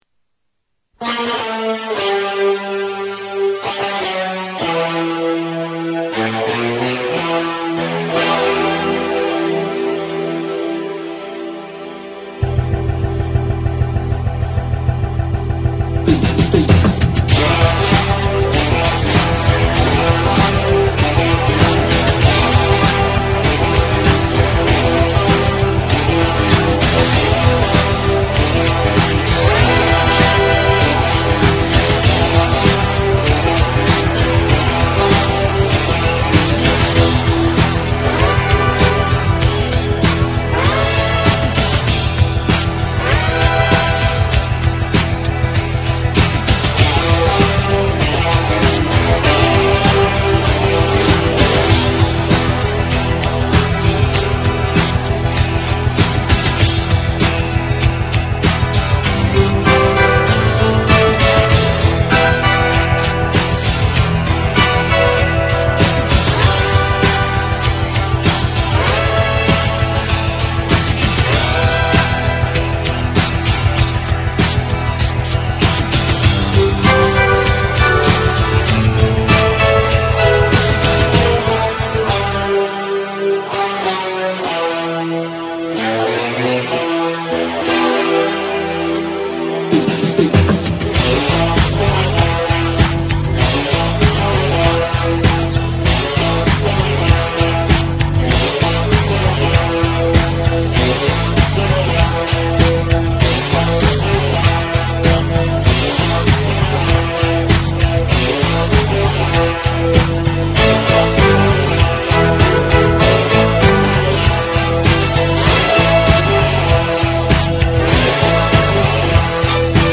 music